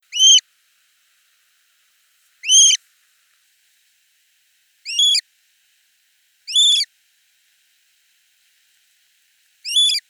Pterodroma macroptera - Petrel apizarrado
Pterodroma macroptera - Petrel apizarrado.wav